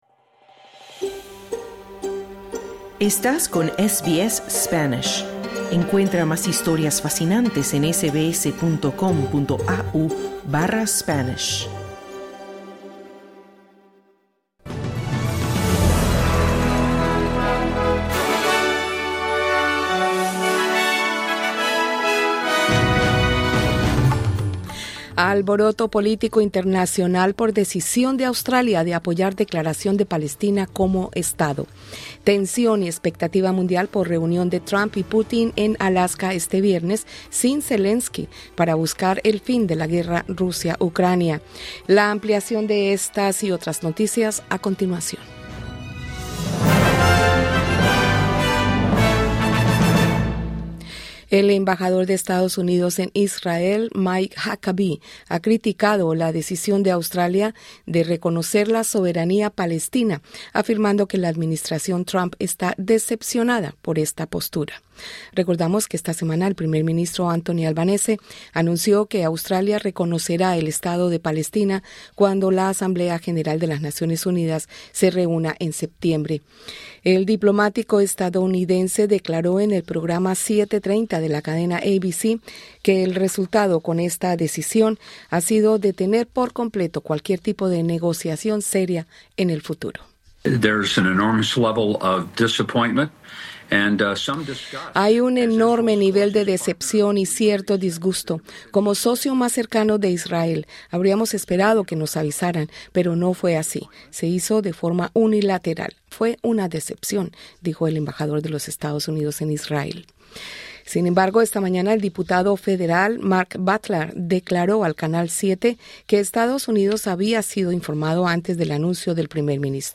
Titulares de este boletín de noticias: El presidente ruso, Vladimir Putin, y su par estadounidense Donald Trump, abordarán formas de resolver el conflicto con Ucrania durante su reunión este viernes en Alaska. Los australianos consumieron más de 20 toneladas de drogas ilegales por valor de miles de millones de dólares entre agosto de 2023 y 2024.